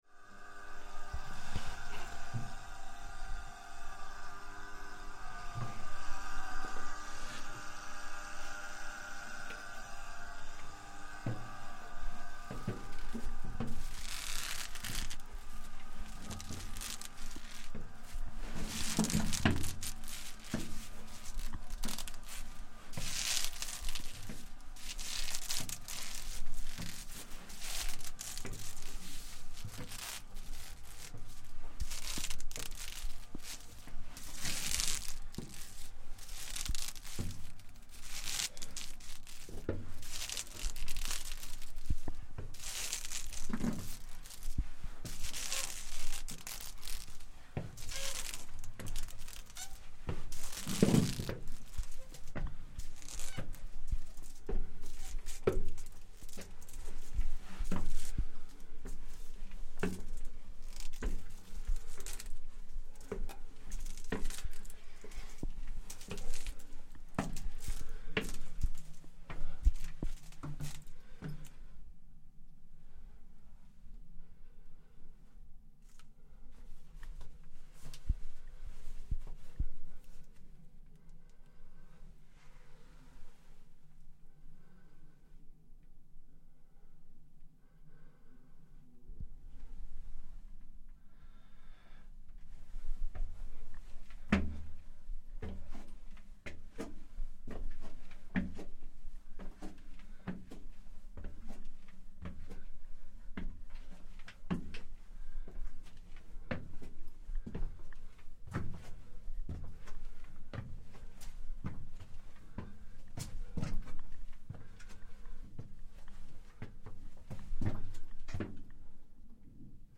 Climbing up the medieval spire of St. Olaf's church - a 258-step climb, during which you can clearly hear the creak of the large rope banister we use to haul ourselves up the stairs as we record (as well as the sound of heavy breathing!).